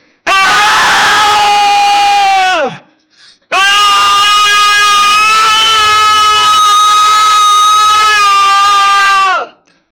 A man screaming in a high-pitched, embarrassing way.
a-man-screaming-in-a-vspwozyz.wav